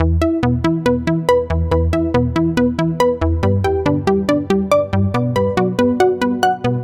低音中音合成器循环
Tag: 140 bpm Chill Out Loops Bass Synth Loops 1.15 MB wav Key : E